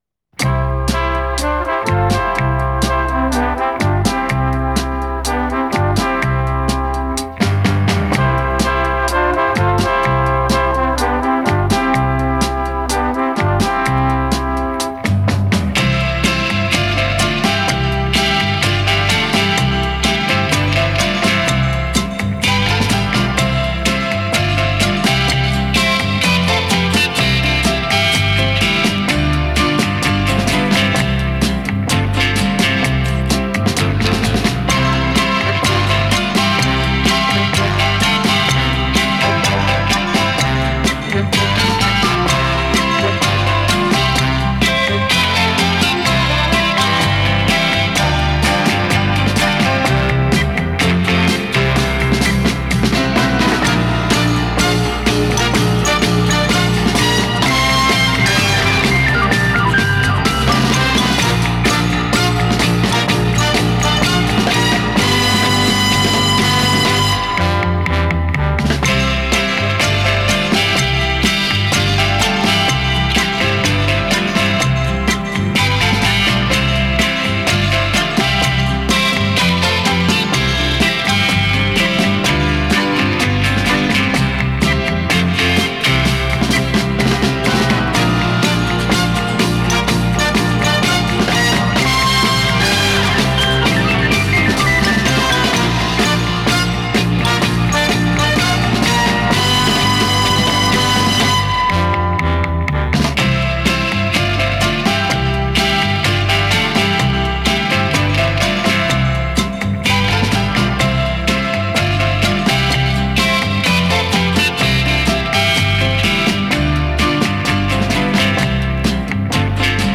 Это  джингл   радиостанции(музыкальная заставка)